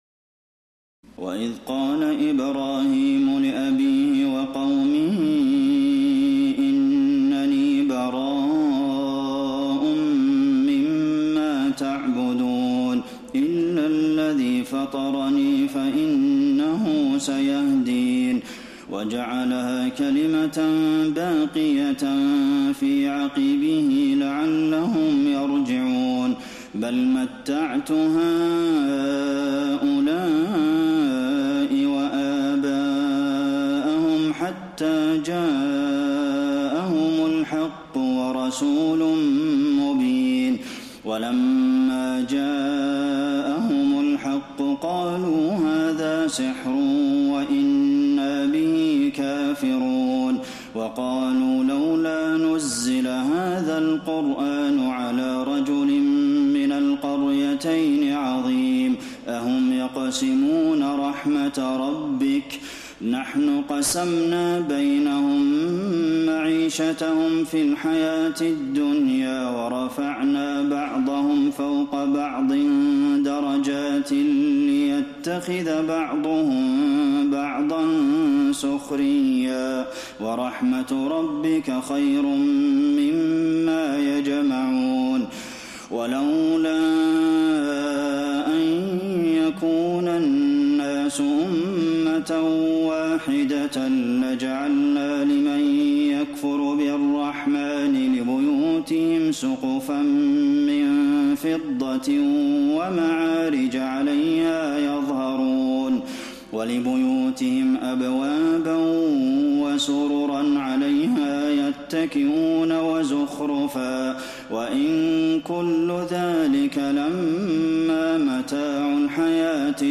تراويح ليلة 24 رمضان 1432هـ من سور الزخرف (26-89) والدخان و الجاثية Taraweeh 24 st night Ramadan 1432H from Surah Az-Zukhruf and Ad-Dukhaan and Al-Jaathiya > تراويح الحرم النبوي عام 1432 🕌 > التراويح - تلاوات الحرمين